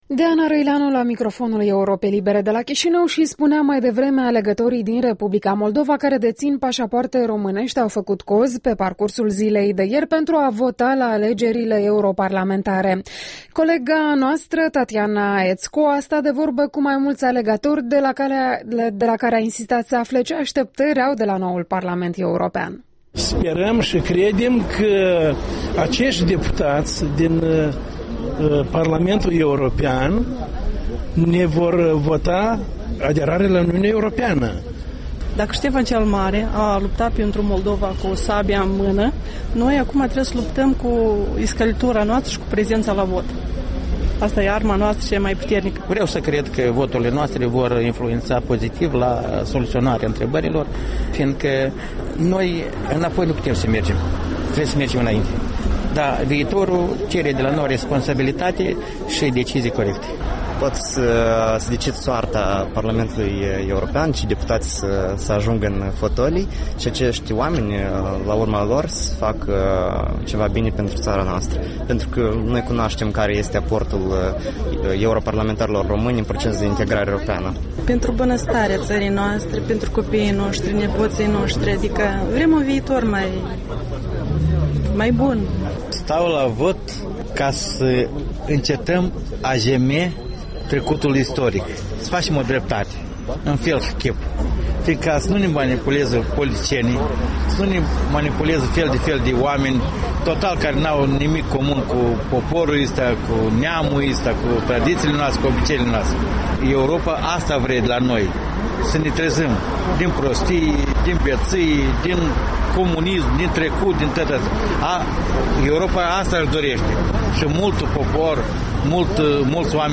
Pulsul alegătorilor de la Chișinău